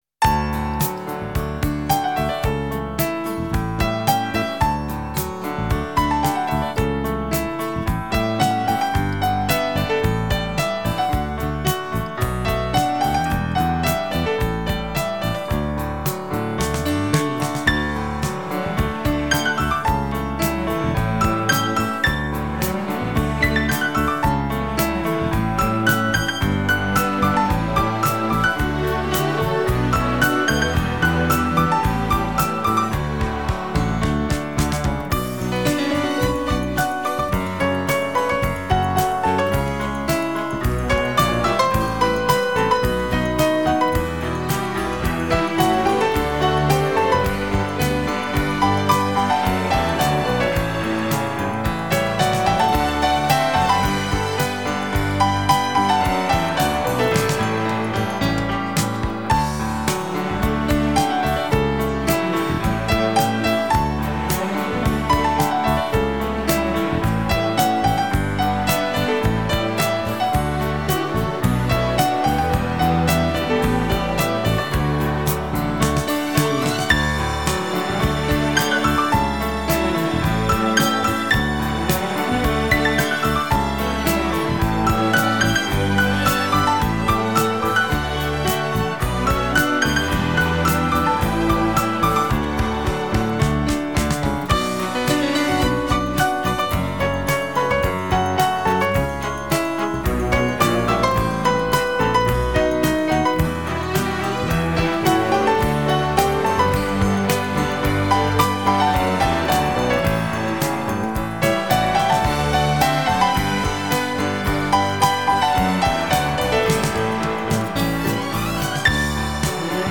专辑风格：器乐、钢琴
扣人心韵、深情、浪漫、的琴声重新演绎经典浪漫金曲